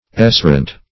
Search Result for " essorant" : The Collaborative International Dictionary of English v.0.48: Essorant \Es"so*rant\, a. [F.]